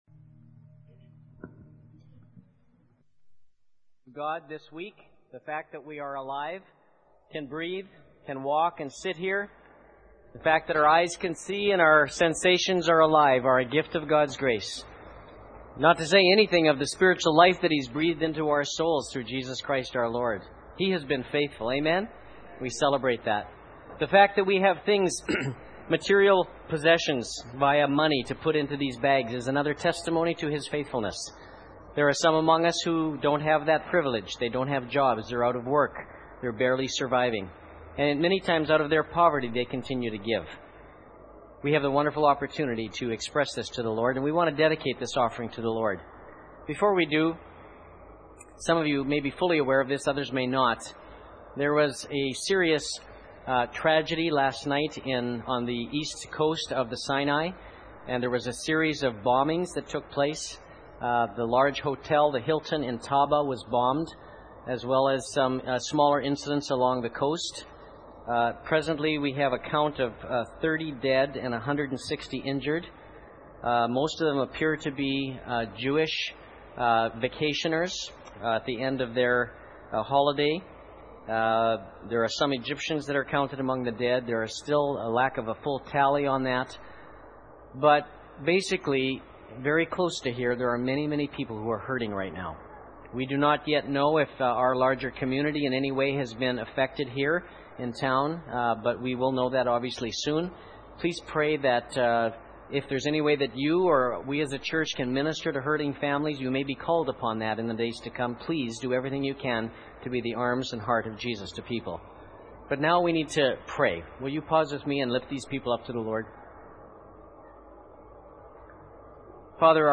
Laser Language Preacher